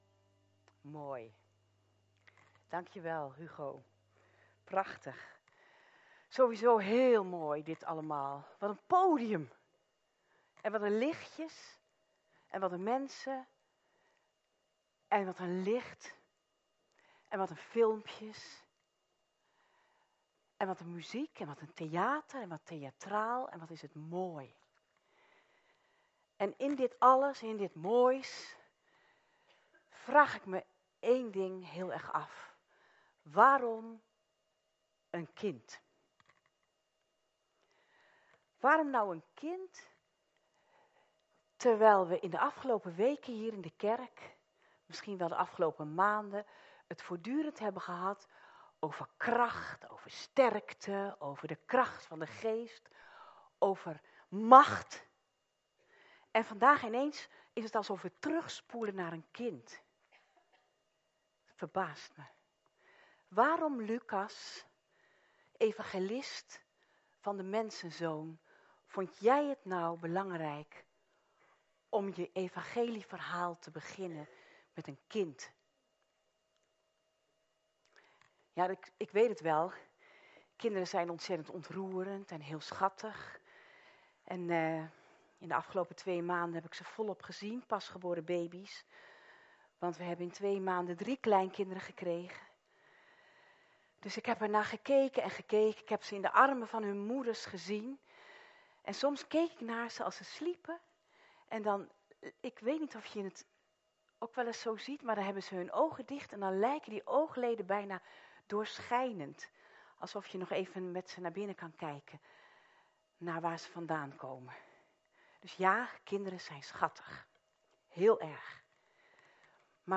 Kerstnachtdienst Jezus begon klein